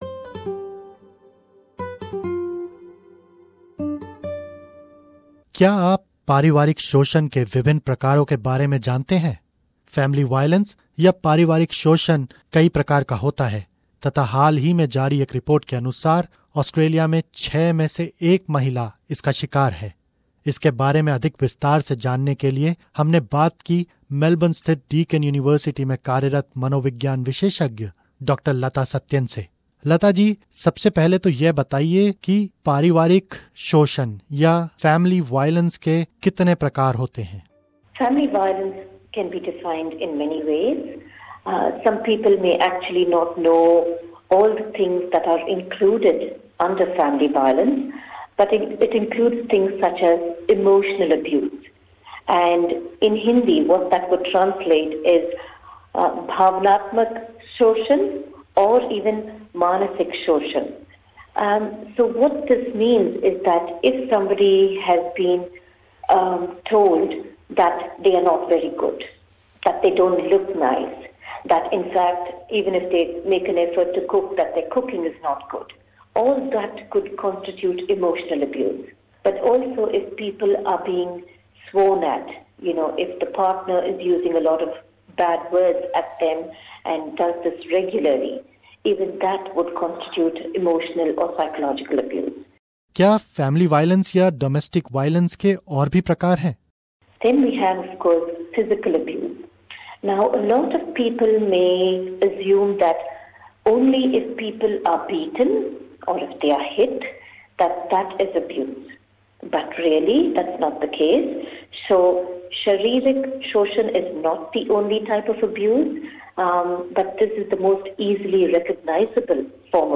SBS Hindi